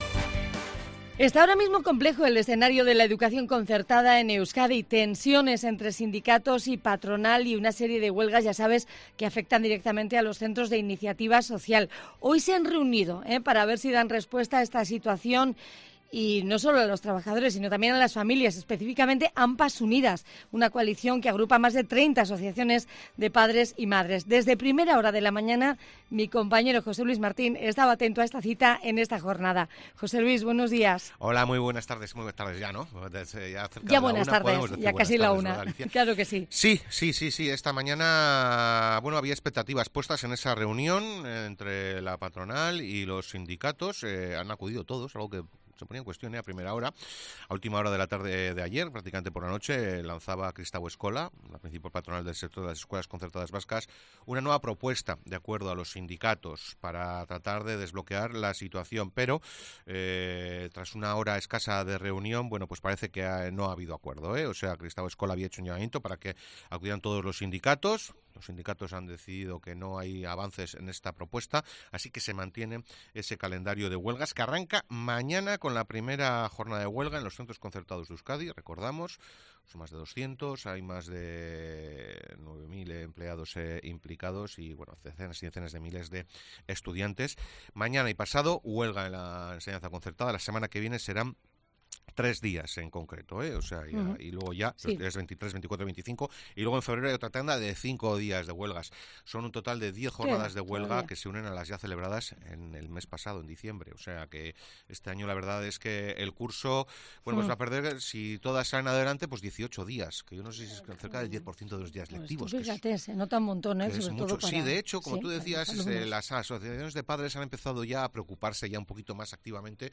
Varias madres de alunmnos de la enseñanza concertada explican en COPE Euskadi su preocupación ante unan ueva tanda de 10 días de huelga y sus efectos en la calidad de la enseñanza